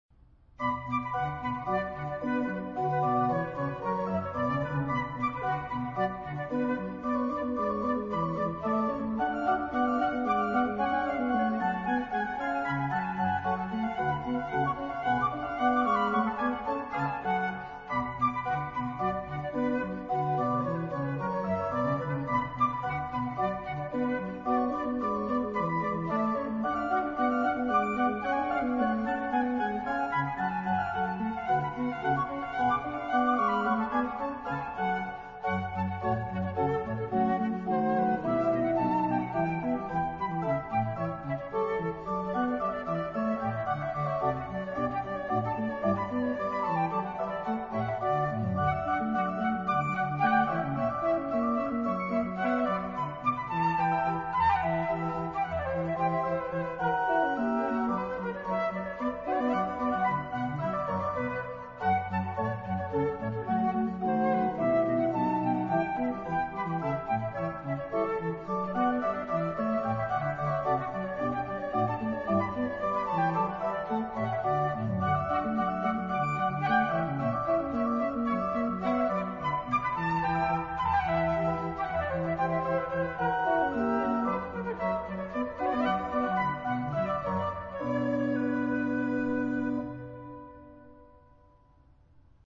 音響はオルガンの美しいパイプと石造りの建築が産みだすのだ、という事を痛感する。
場所：聖ボニファシウス教会（オランダ、メデンブリック）